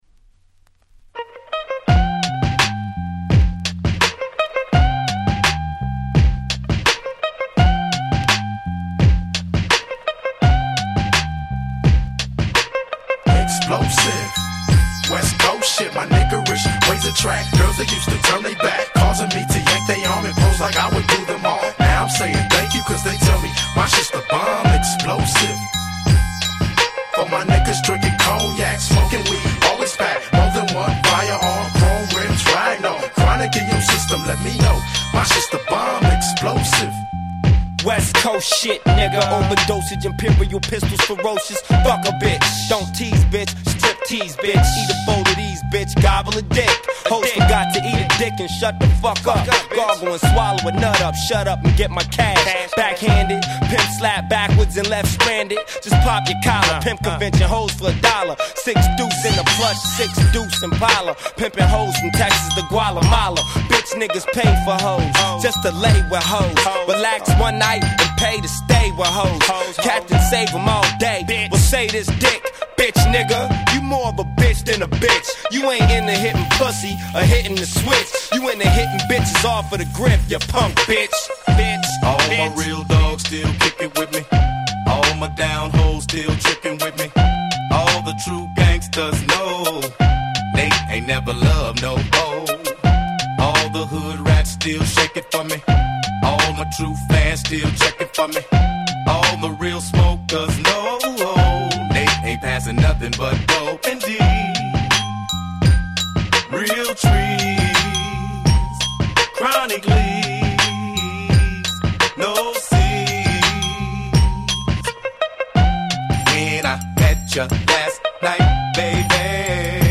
G-Rap Gangsta Rap